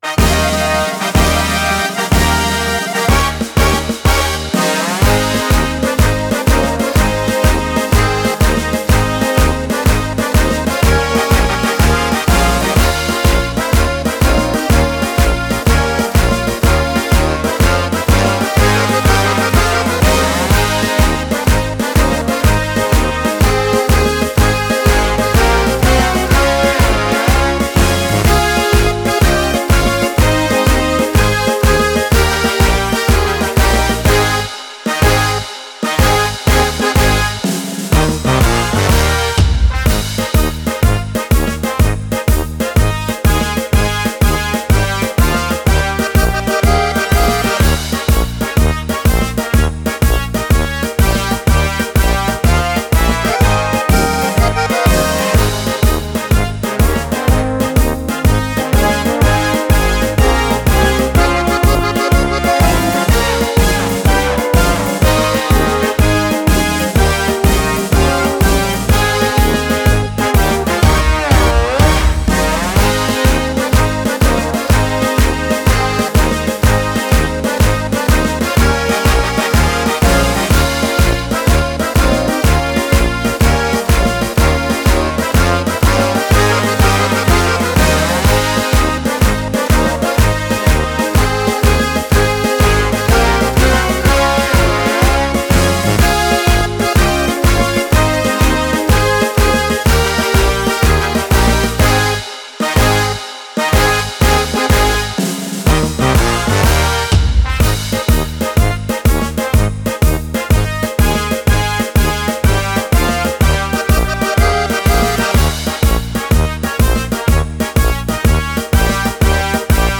En daar bleef het dan bij…. tot nu….. want ons clublied is in een nieuw jasje gestoken met onder andere twee lege coupletten en het is aan jullie om deze jaarlijks opnieuw te gaan vullen en opnieuw te komen shinen tijdens ons Songfestival op het Ellufde van de Ellufde feest.
Het nummer begint met het bekende traditionele refrein van “De mannen van de Meekrap”, zoals dat hierboven staat.